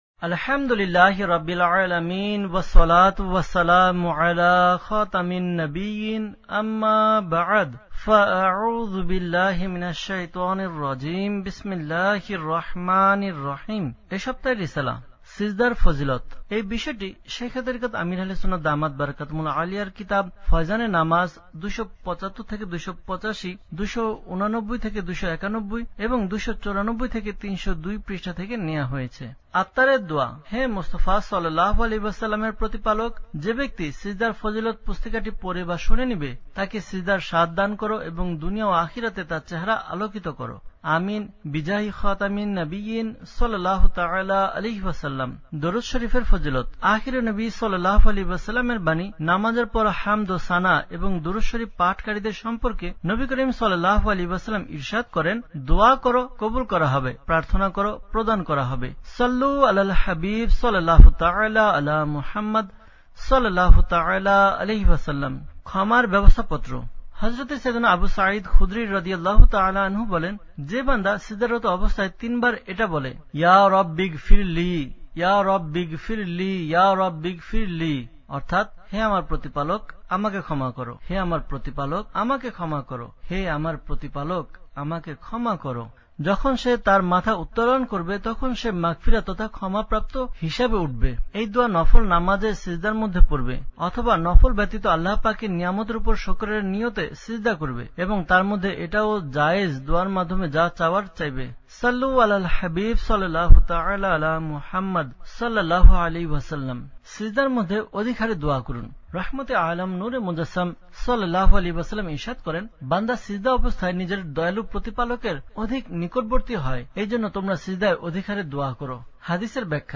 Audiobook – সিজদার ফযীলত (Bangla)